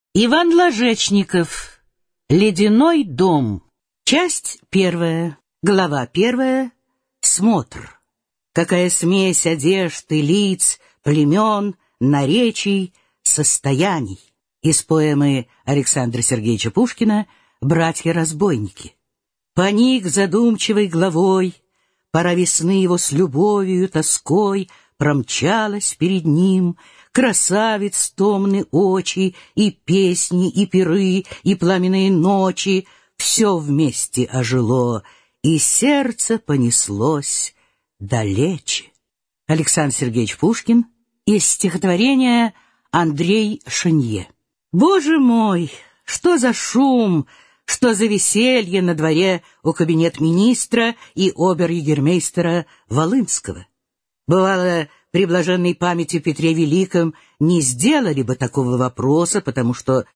Аудиокнига Ледяной дом | Библиотека аудиокниг
Прослушать и бесплатно скачать фрагмент аудиокниги